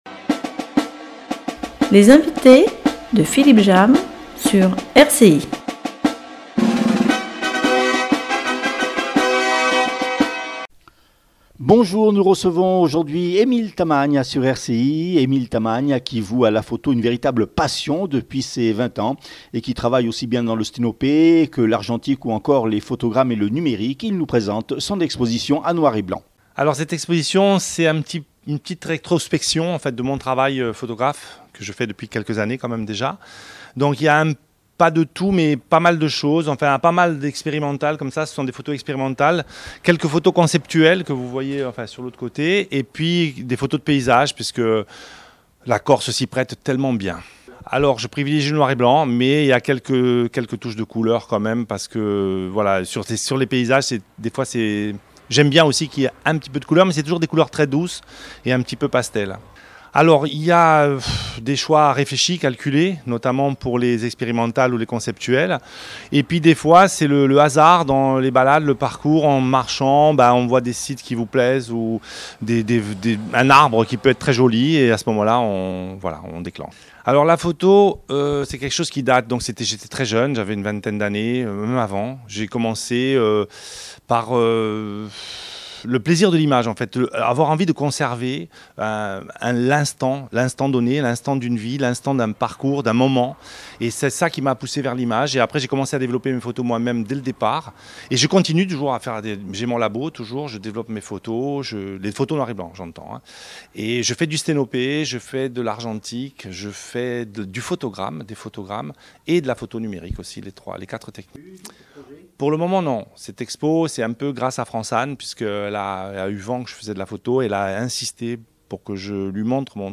REPORTAGE GALERIE NOIR ET BLANC